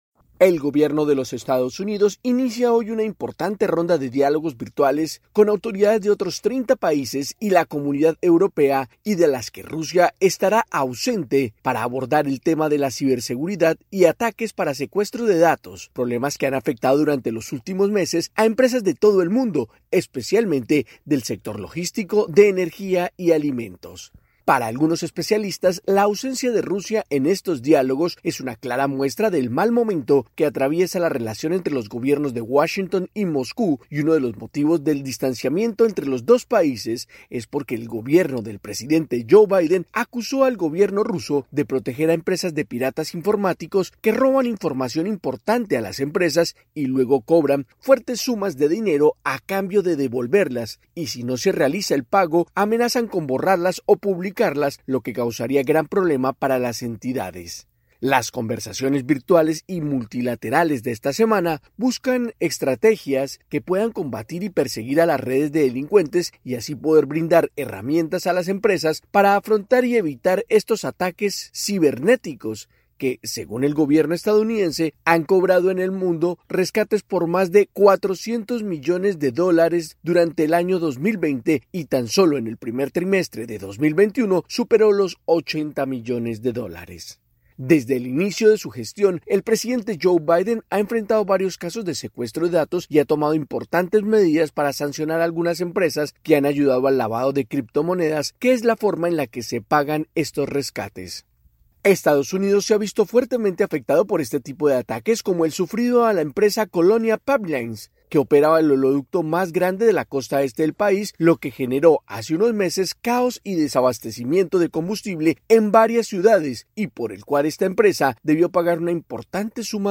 desde la Voz de América en Washington, DC.